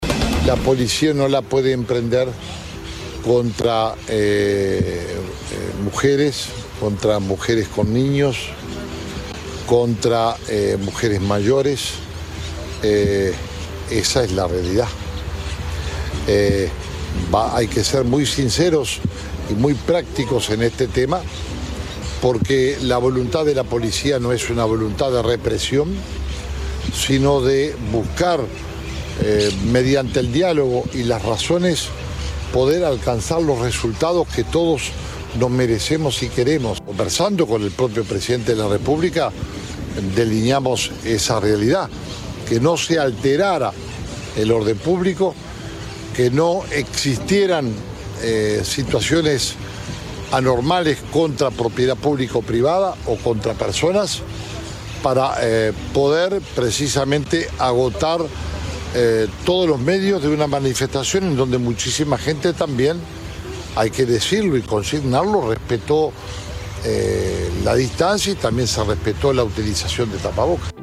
«Que no existieran situaciones anormales contra propiedad pública o privada o contra personas», aclaró en rueda de prensa.
Escuche lo expuesto por Jorge Larrañaga sobre el 8M